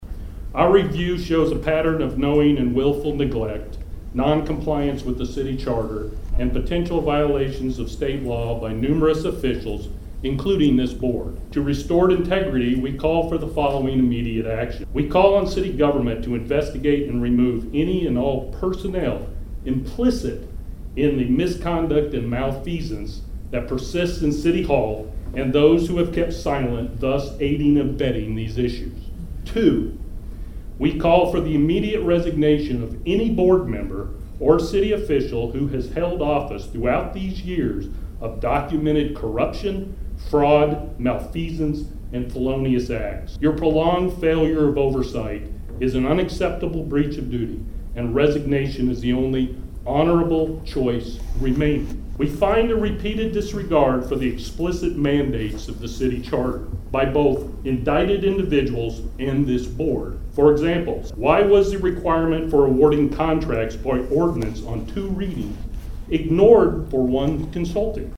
Approximately 75 community members attended  a special called Martin City Board Meeting  last night at the  Martin Municipal Building where acting Mayor David Belote held an open forum to allow residents who share their concerns about City Hall.